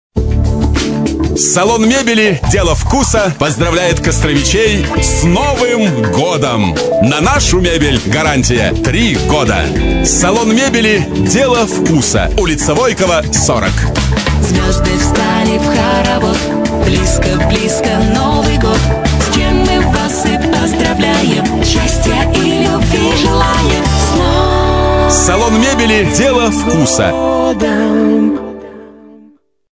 Рекламные радио ролики записаны в формате mp3 (64 Kbps/FM Radio Quality Audio).
(Голос "Презентация"). 30 сек. 232 кбайт.